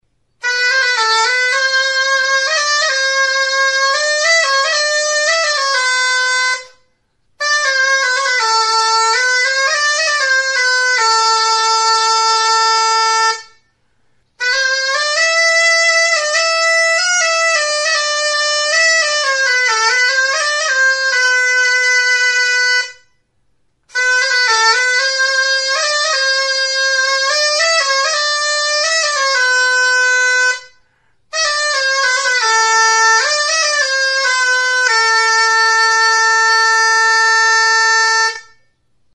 Music instrumentsTxanbela
Aerophones -> Reeds -> Double (oboe)
Recorded with this music instrument.
Mihi bikoitzeko soinu-tresna da.